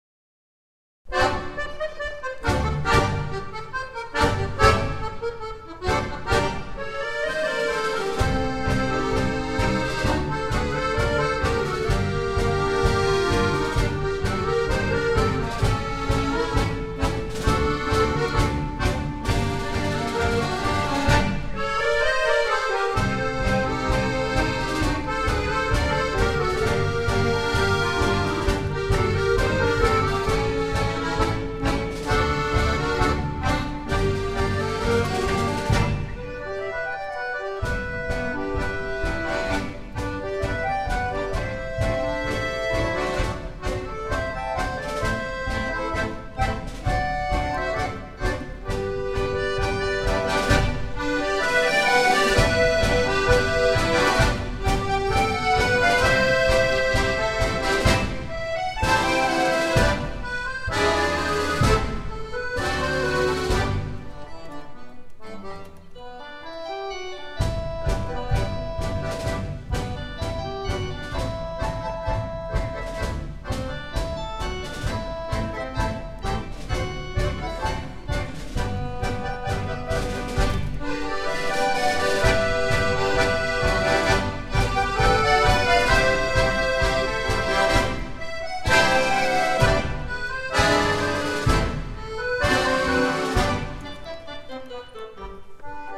2014 – Akkordeonorchester Neustadt bei Coburg e. V.